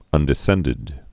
(ŭndĭ-sĕndĭd)